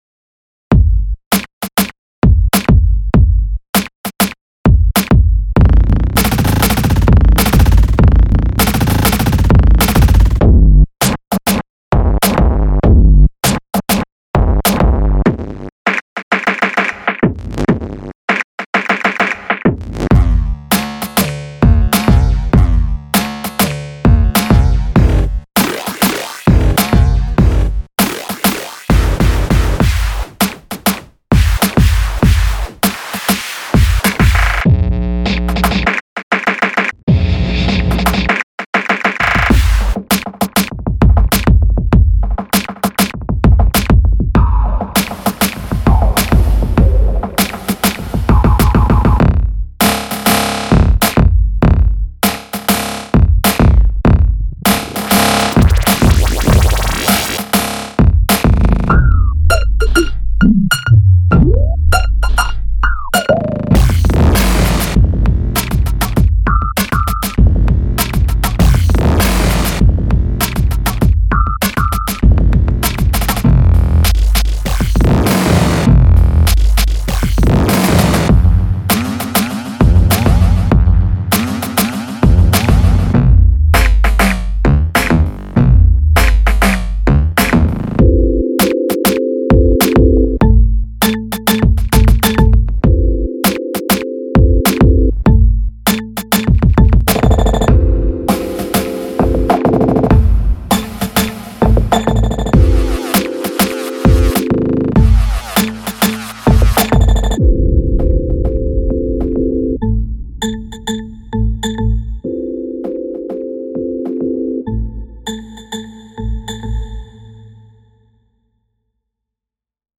Everything you are hearing, including any bass, keyboard or rhythm fx sounds, are all derived from that simple kick and snare sound.
All the fast edits you are hearing are from the Granular menu using "Auto Freeze Pan" , "Freeze Boing" and the "Stutter Pan" function in SFX Machine Pro.
In the demo you can hear that each consecutive two bars changes and utilizes different parameters and fx. Sometimes I have stacked two or three SFX Machine Pro's on the same channel and automated different parameters including distortion, flanger or echo fx to create interesting textures and echos.
sfx_machine_pro_demo.mp3